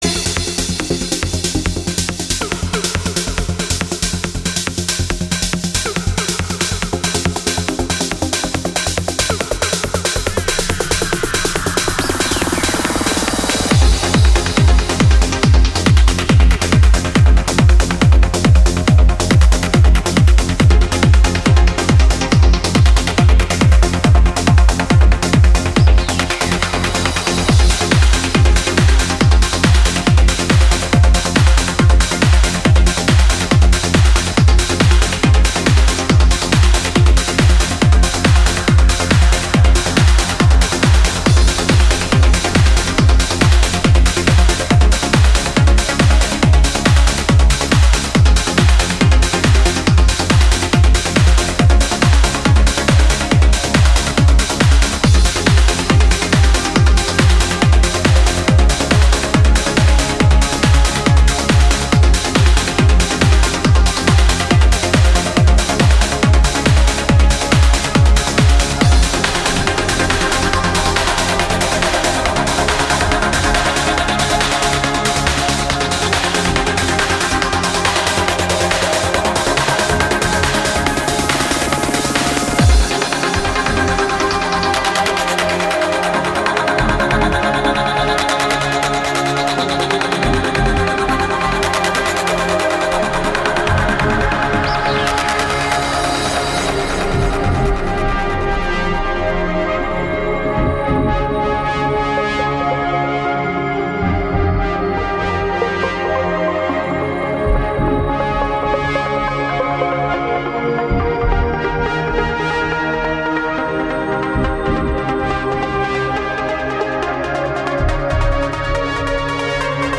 Instrumental mix